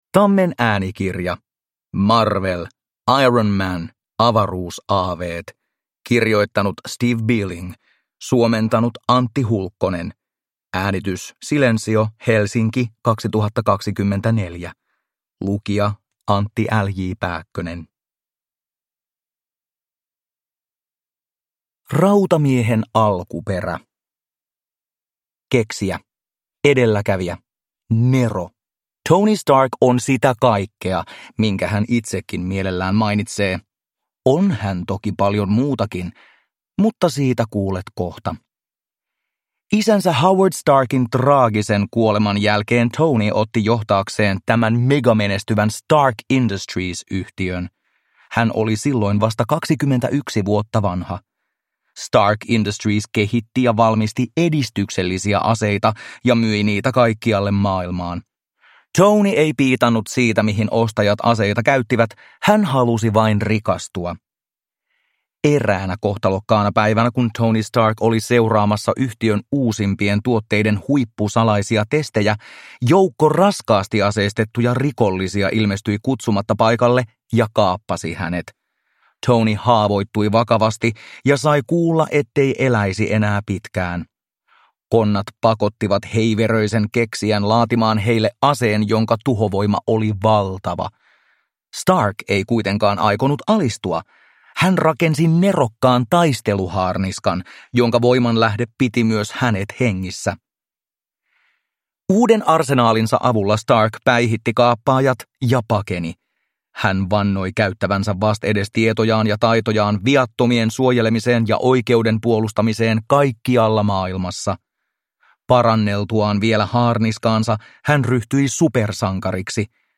Marvel. Iron Man. Avaruusaaveet – Ljudbok